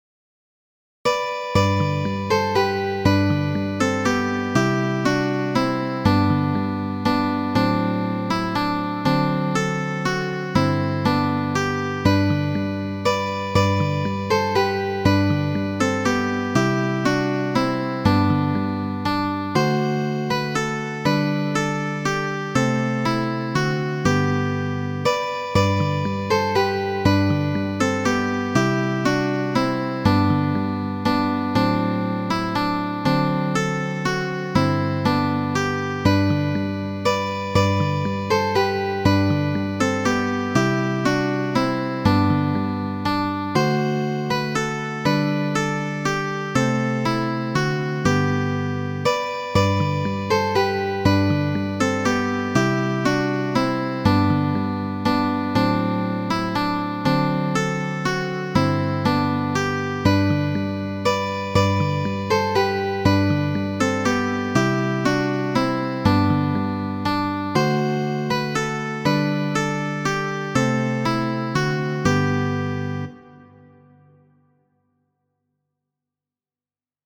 Restu kun mi, usona popolkanto, en aparta versio de mi mem.